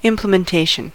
implementation: Wikimedia Commons US English Pronunciations
En-us-implementation.WAV